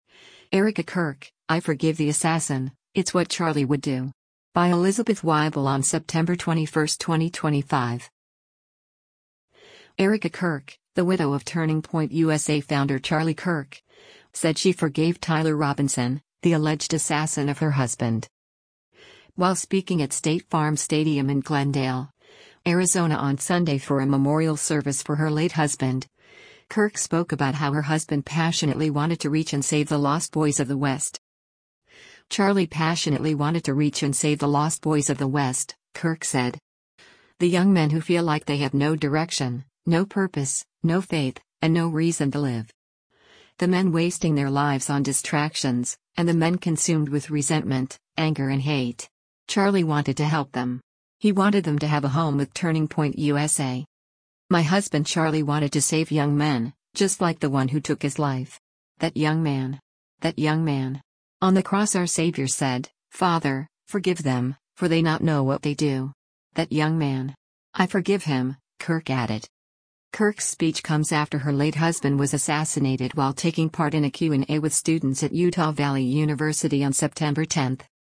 While speaking at State Farm Stadium in Glendale, Arizona on Sunday for a memorial service for her late husband, Kirk spoke about how her husband “passionately wanted to reach and save the lost boys of the west.”